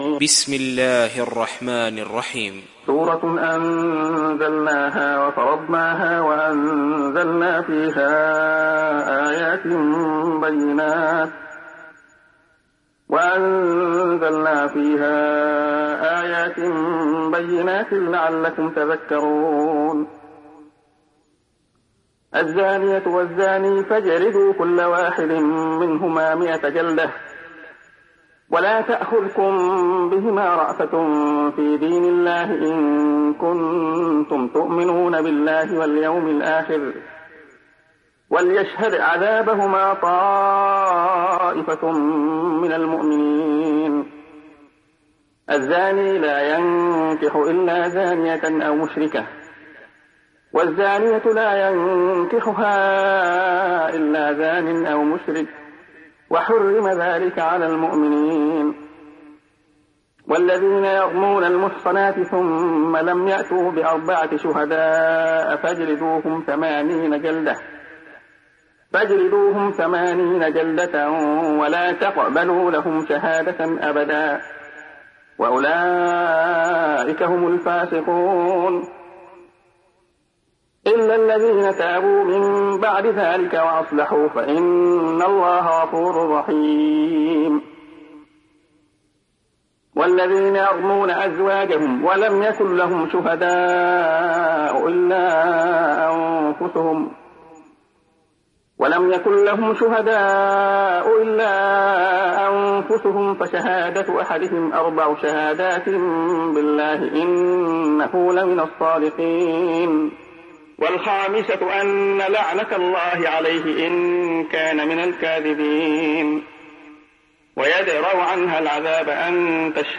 دانلود سوره النور mp3 عبد الله خياط (روایت حفص)